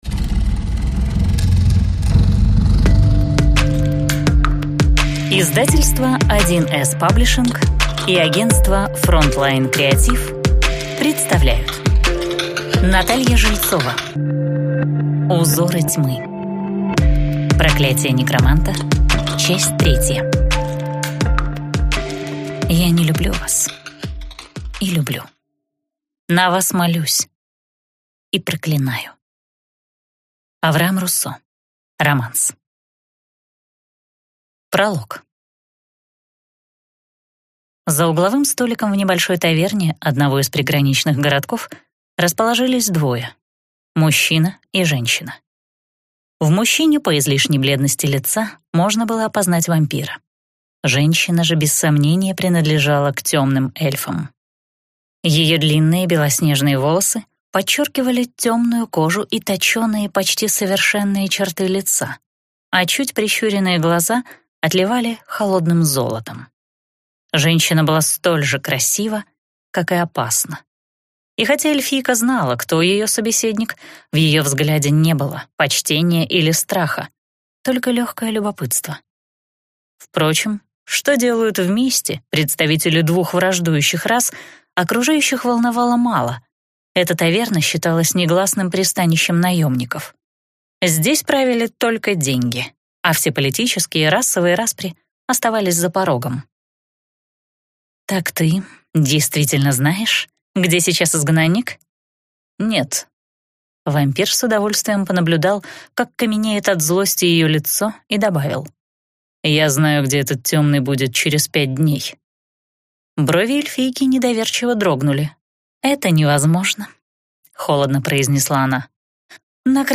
Аудиокнига Узоры тьмы - купить, скачать и слушать онлайн | КнигоПоиск